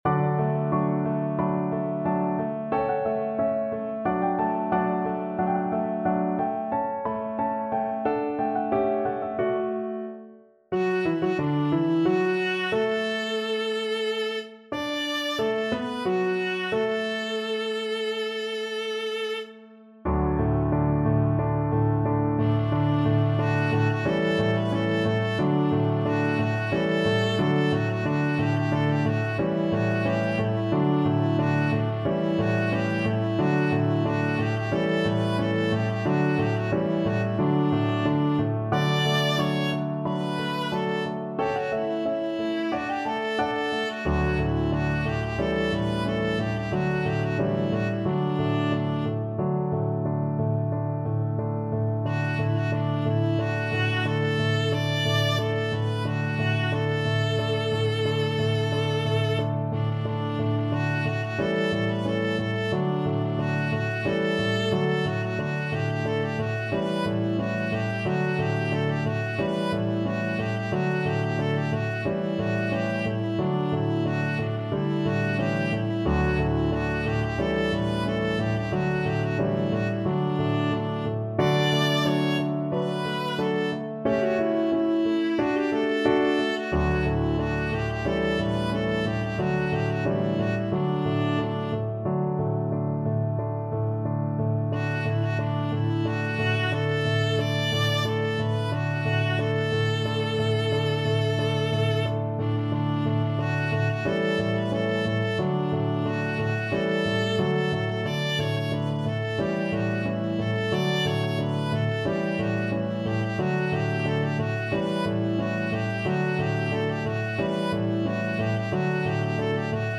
Allegro con brio =90 (View more music marked Allegro)
2/2 (View more 2/2 Music)
Classical (View more Classical Viola Music)